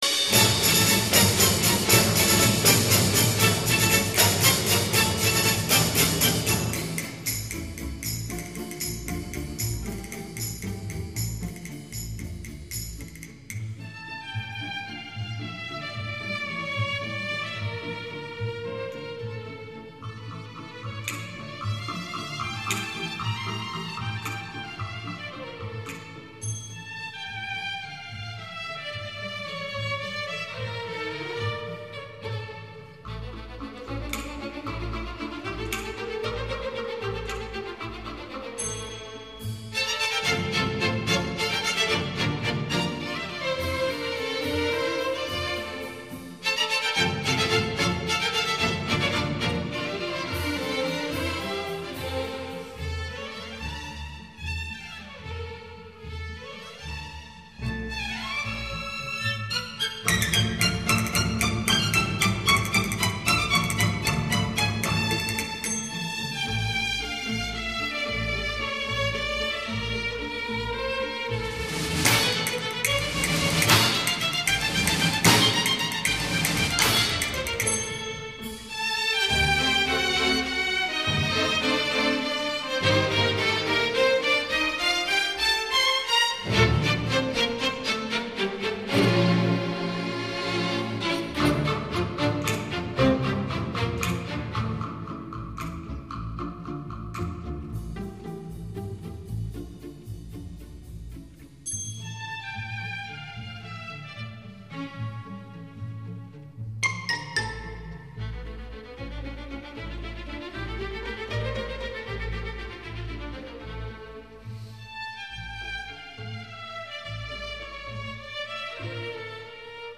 所属分类：发烧音乐